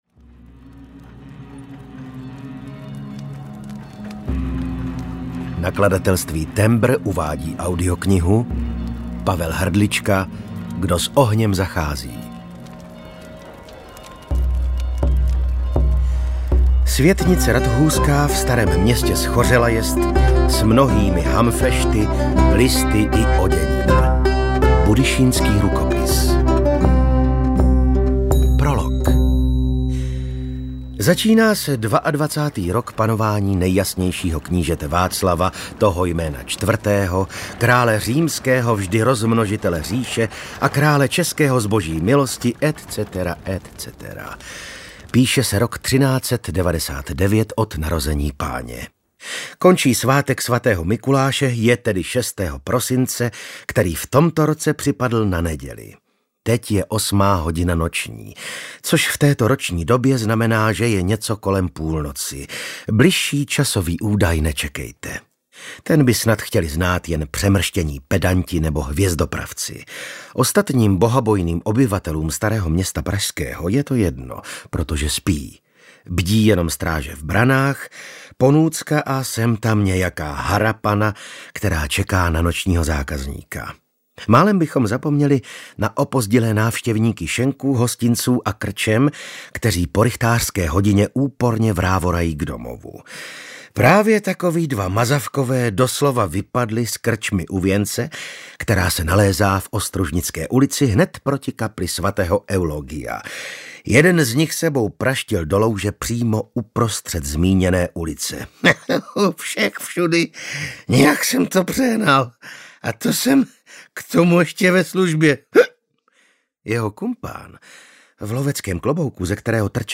Audio knihaKdo s ohněm zachází
Ukázka z knihy
• InterpretVasil Fridrich